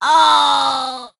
penny_die_vo_03.ogg